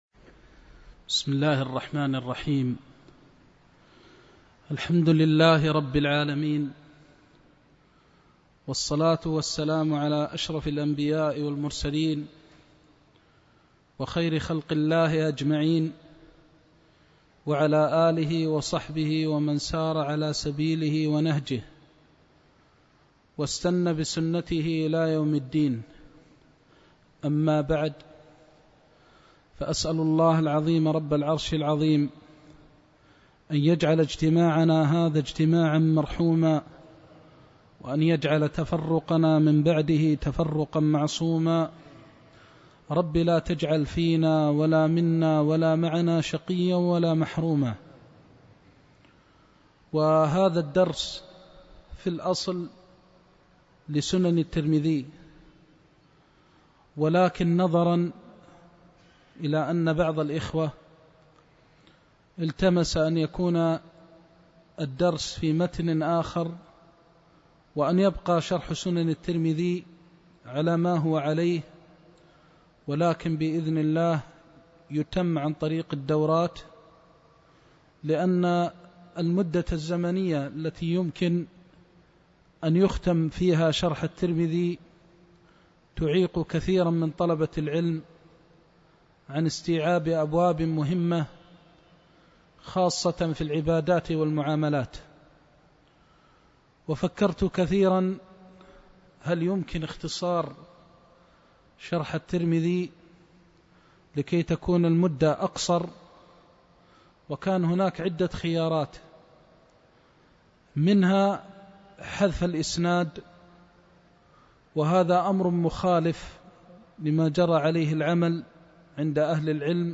درس عمدة الأحكام(جدة) لفضيلة الشيخ محمد محمد المختار الشنقيطي